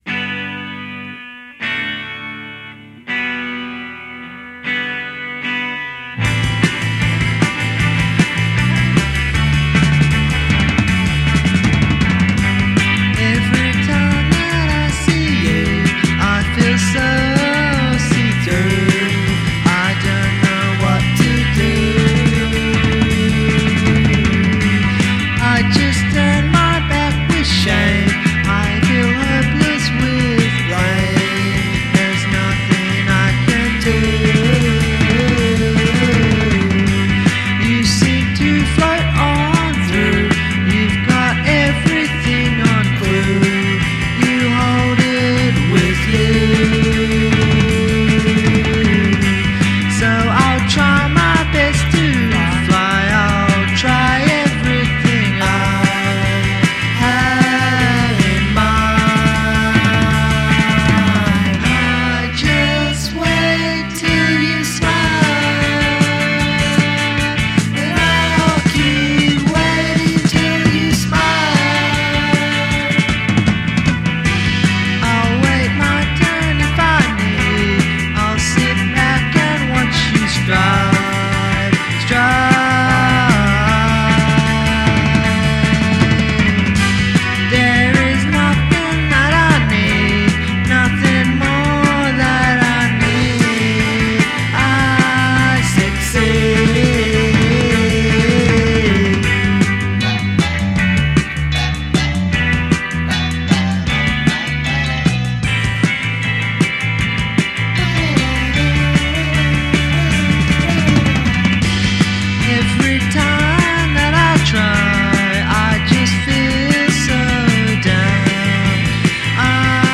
e altri poetici e ostinati alla Television Personalities